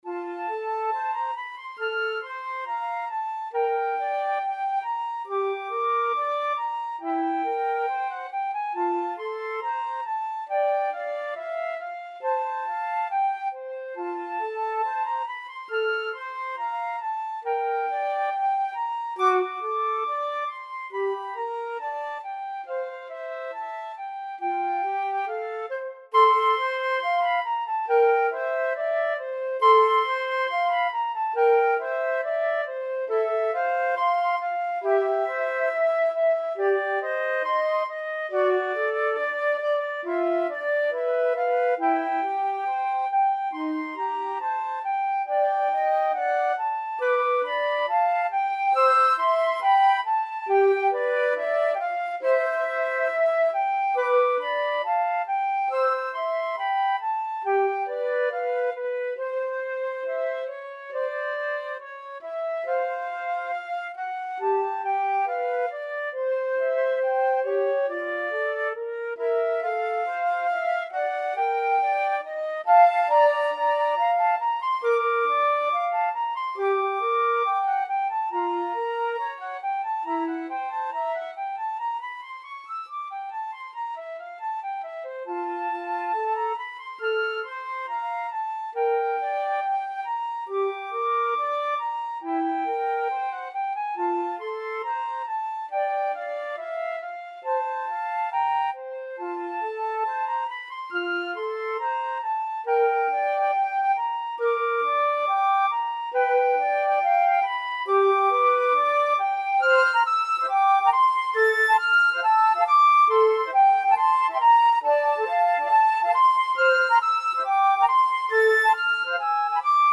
Free Sheet music for Flute Duet
F major (Sounding Pitch) (View more F major Music for Flute Duet )
~ = 69 Allegro grazioso (View more music marked Allegro)
2/4 (View more 2/4 Music)
Classical (View more Classical Flute Duet Music)